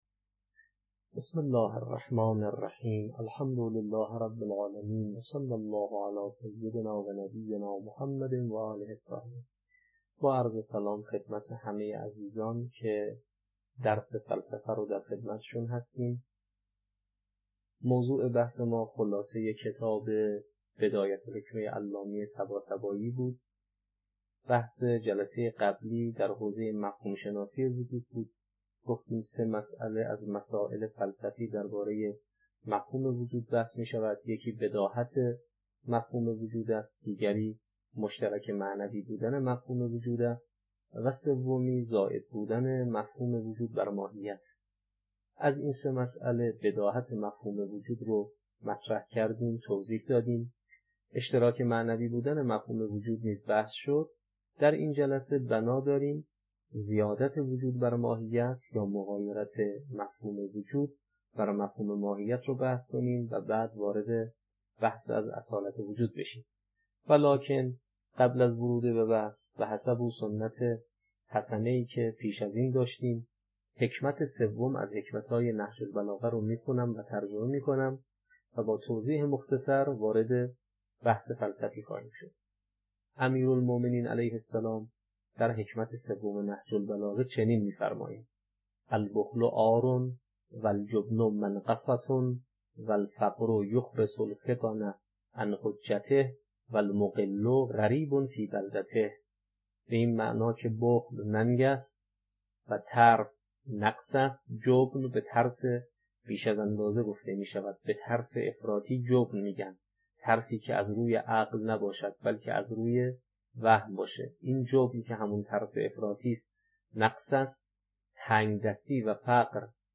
التمهيد في الحكمة الهية (خلاصه بدایه الحکمه) - تدریس